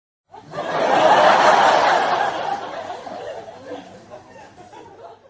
Звуки закадрового смеха